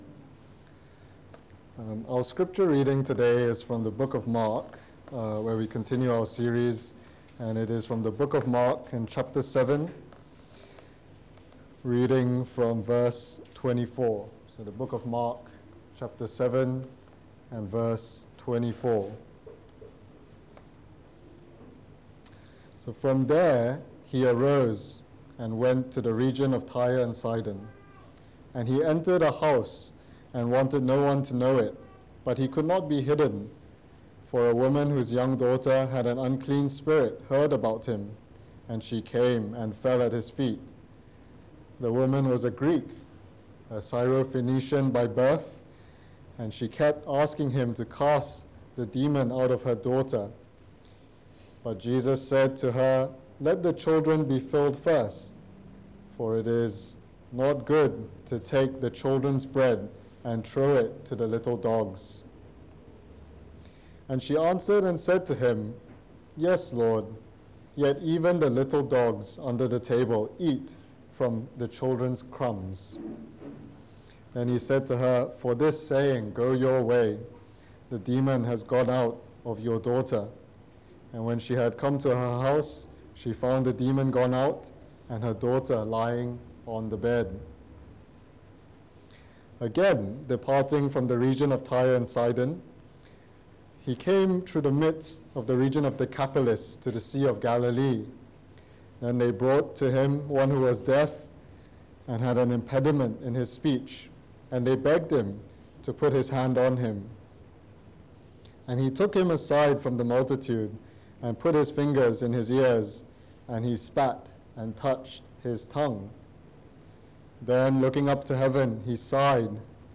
Preached on the 24 of October 2021 on the gospel of Mark delivered for the Sunday Evening Service